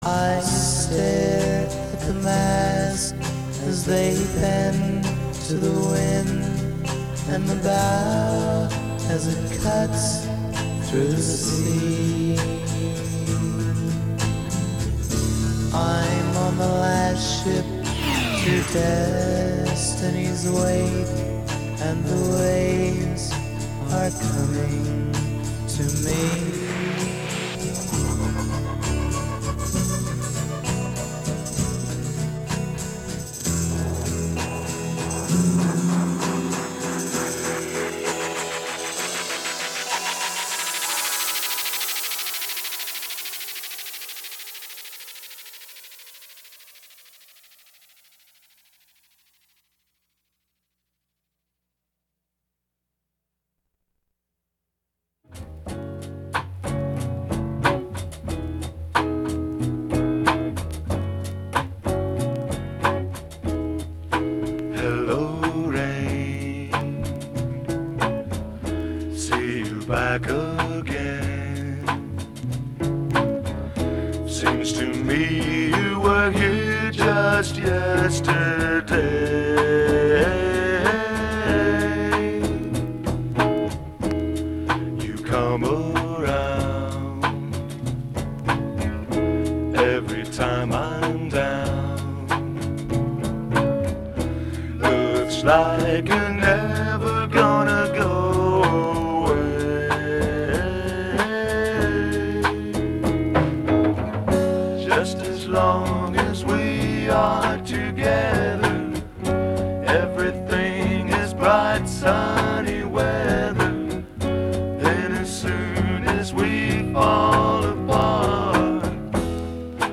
All original vinyl, from all over the world.